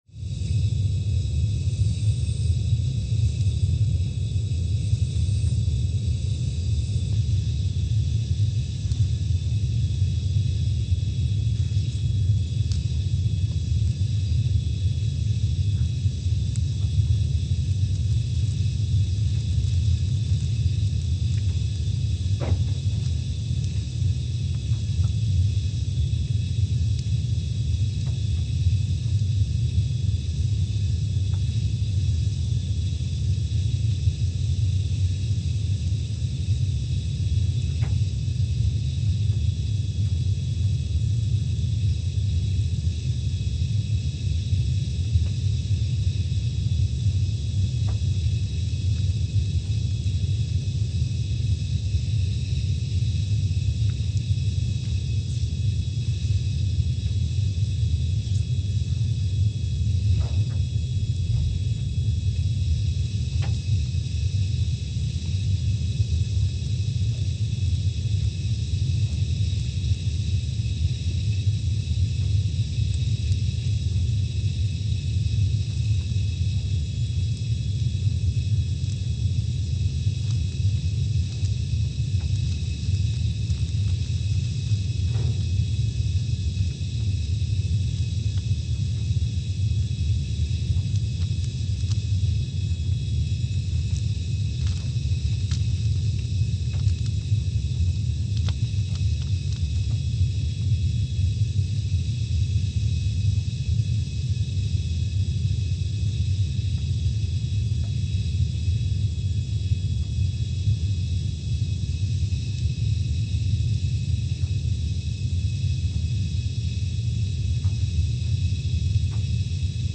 Scott Base, Antarctica (seismic) archived on May 10, 2021
No events.
Sensor : CMG3-T
Speedup : ×500 (transposed up about 9 octaves)
Loop duration (audio) : 05:45 (stereo)